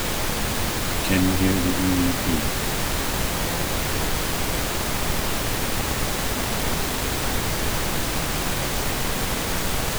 They start out simple, with a decent signal strength but they get progressively harder to make out as the signal becomes weaker and embedded deeper into the noise floor.